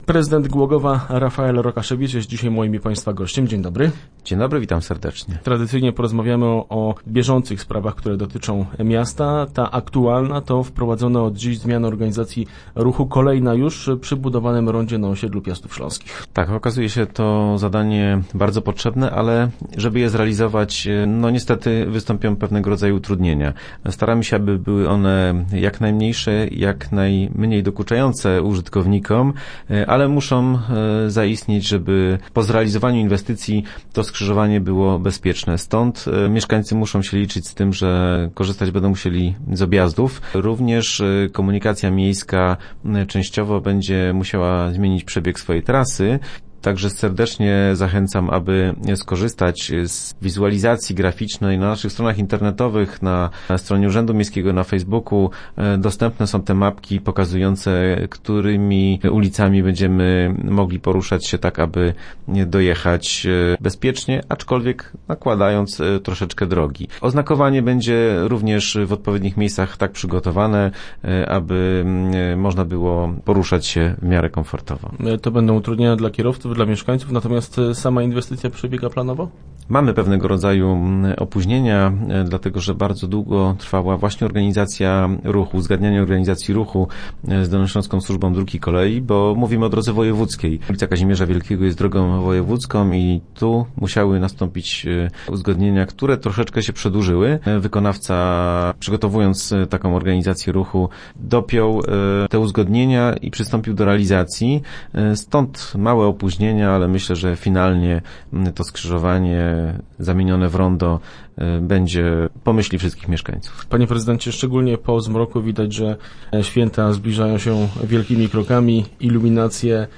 0610_rokasz_re.jpgO inwestycjach, świątecznej iluminacji i bezpieczeństwie pieszych rozmawialiśmy w środę w studiu z prezydentem Głogowa Rafaelem Rokaszewiczem. Włodarz zaprosił też mieszkańców na Barbórkę i Mikołajki.